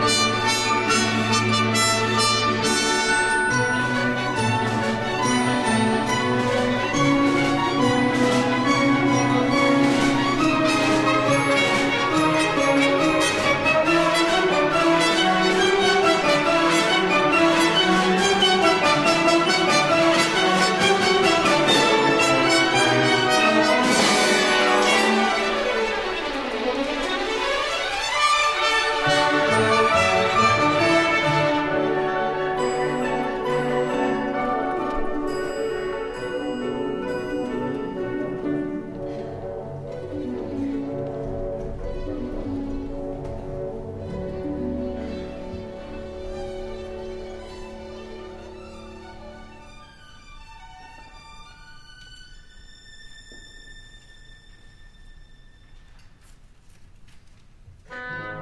Quarter note = 139, After #12 = 112 (until rall.) http